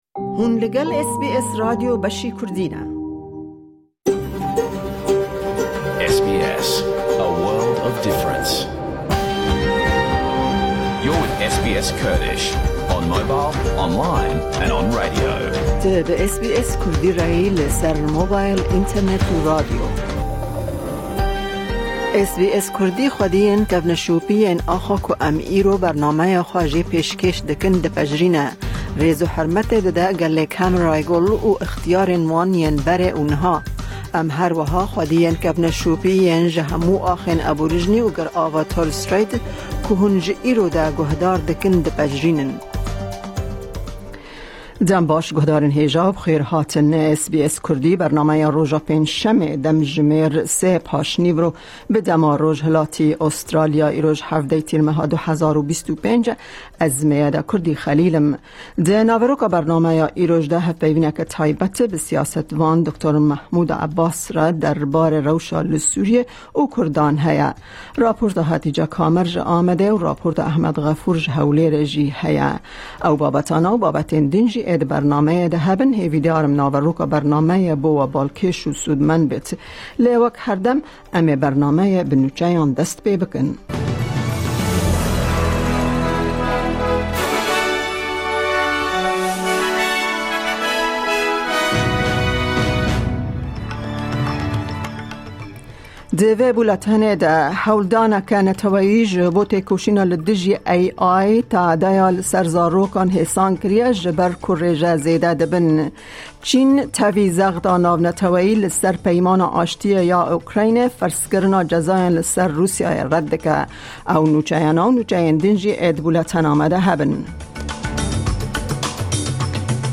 Nûçe, hevpeyvîn, raporên ji Amed û Hewlêre û babetên cur bi cur tê de hene.